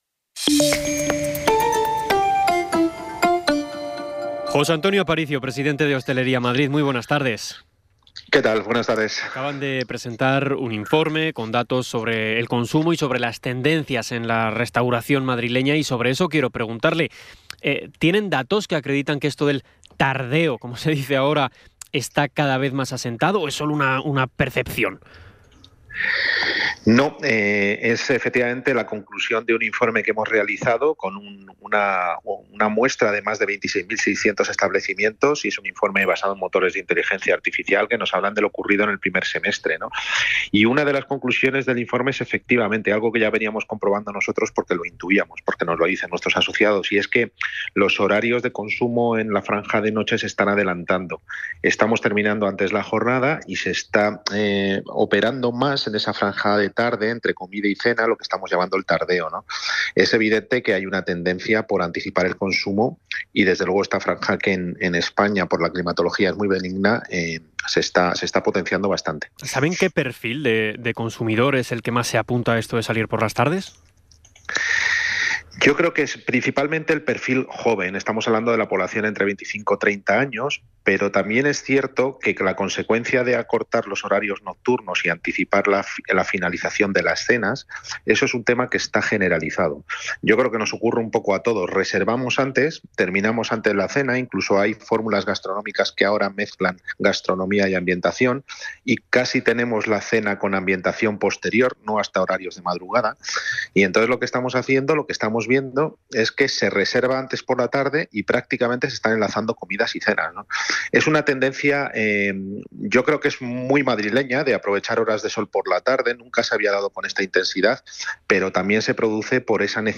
Entrevista LA VENTANA. El «tardeo». Duración: 7:02